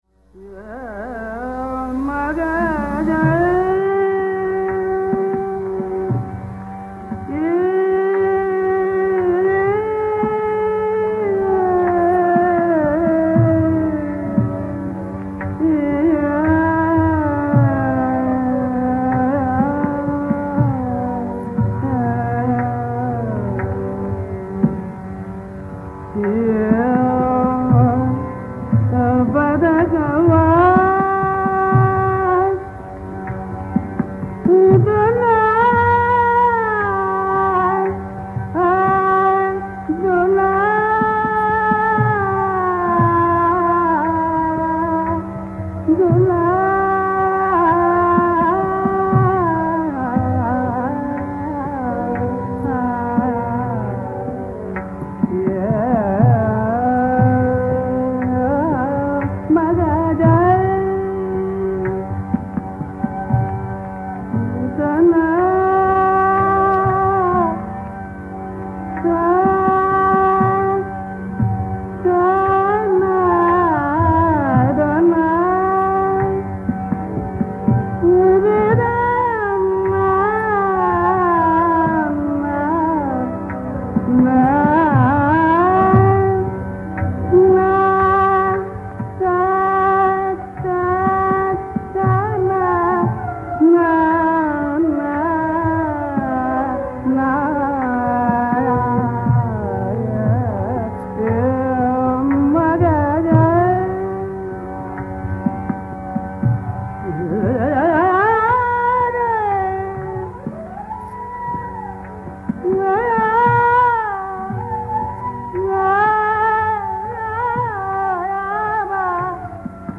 To the casual ear this uncommon raga will sound no different than Khambavati.
Narayanrao Vyas alternates his ascent between G M P D S” and G M P D N. The komal nishad enters via the avarohi S” R” n cluster.